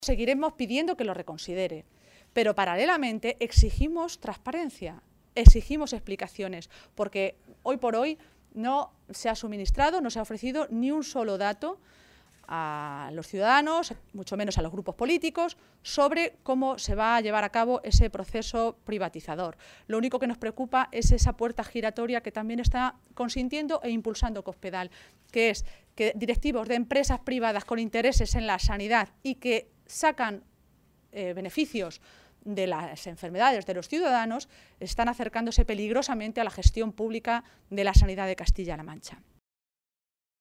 Así lo señalaba Maestre en una comparecencia ante los medios de comunicación, en Toledo, esta tarde, minutos antes de la reunión de la dirección regional del PSOE castellano-manchego.